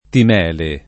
vai all'elenco alfabetico delle voci ingrandisci il carattere 100% rimpicciolisci il carattere stampa invia tramite posta elettronica codividi su Facebook timele [ tim $ le ; alla lat. t & mele ] s. f. (archeol.); pl.